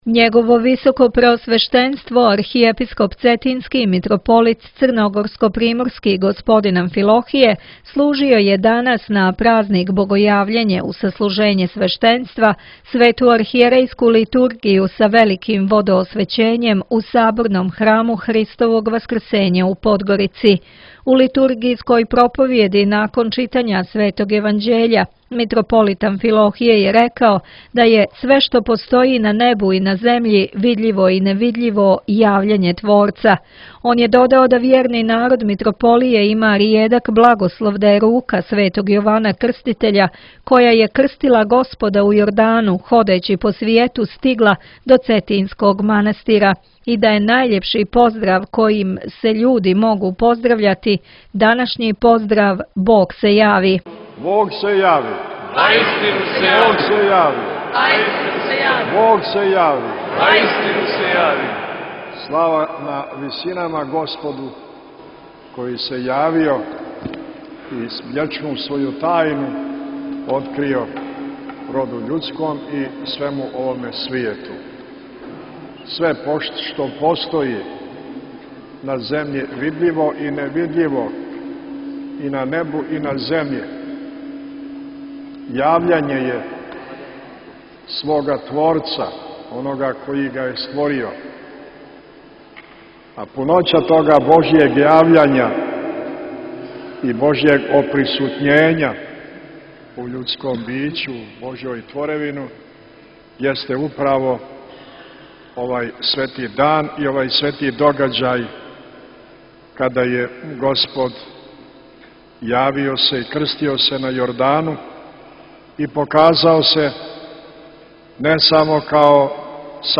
Бесједа Митрополитa Амфилохија на Богојављење у подгоричком Саборном храму
Бесједа Његовог високопреосвештенства Архиепископа цетињског Митрополита црногорско-приморског Г. Амфилохија изговорена на Светој архијерејској литургији коју је на празник Богојављење, у суботу 19. јануара служио у Саботном храму Христовог Васкрсења у Подгорици.